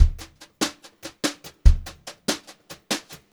144GVBEAT1-L.wav